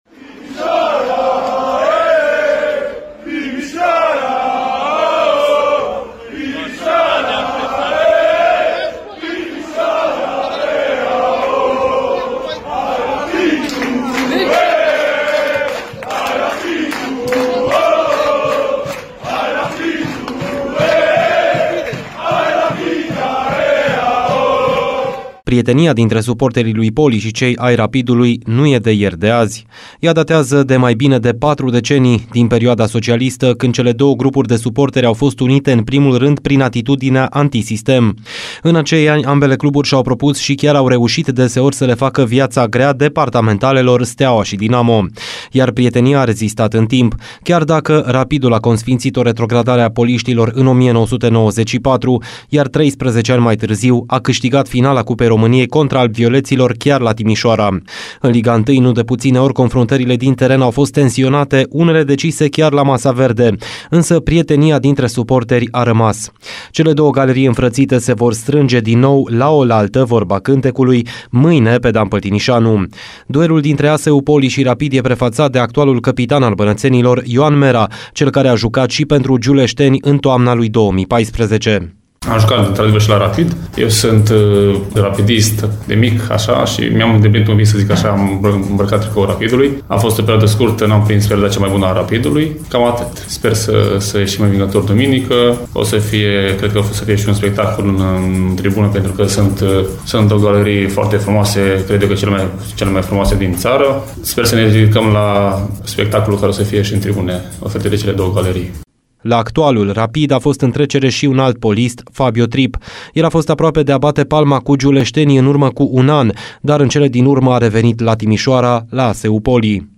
Dintre echipele din zonă mai are de jucat ASU Politehnica, mâine, de la ora 11, cu Rapid București pe stadionul Dan Păltinișanu. Avancronica partidei de mâine